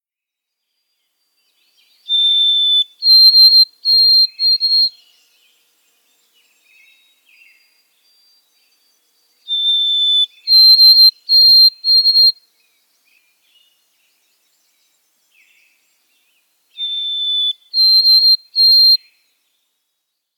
White-throated Sparrow
How they sound: White-throated Sparrows sing a pretty, thin whistle that sounds like Oh-sweet-canada-canada.